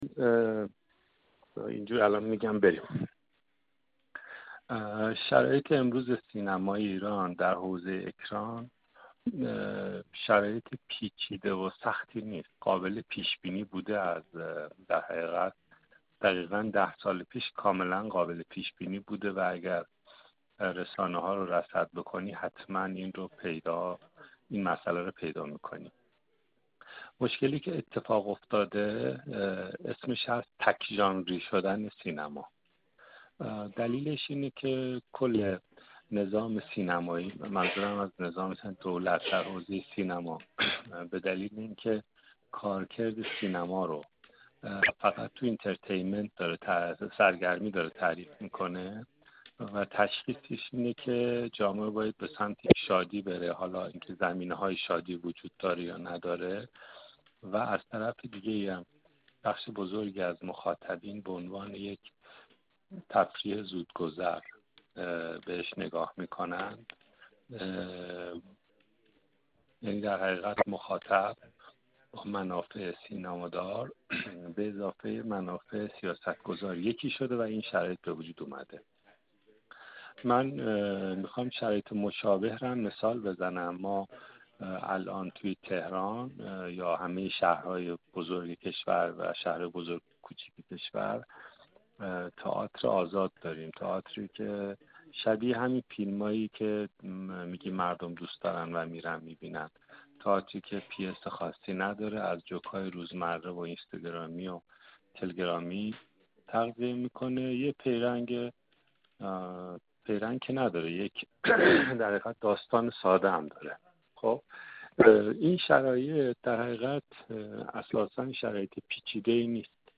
گفت‌و‌گویی انجام داده